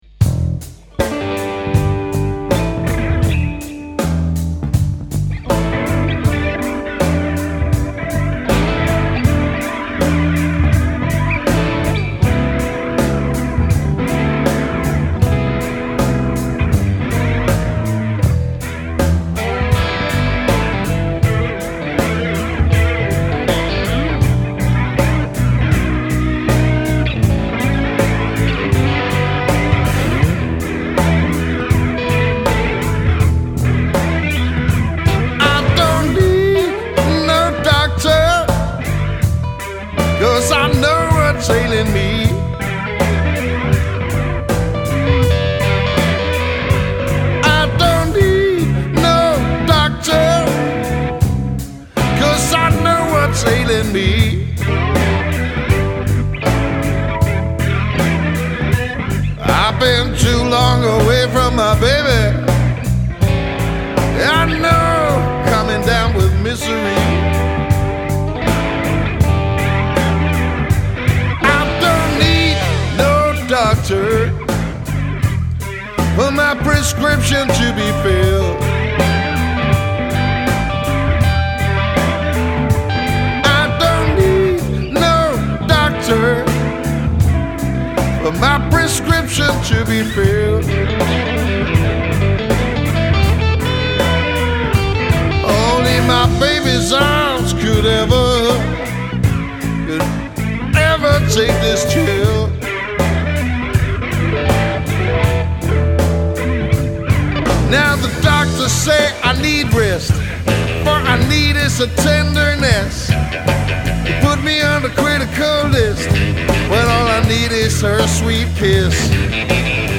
Når festen skal emme af Mississippi og Rock 'n' Roll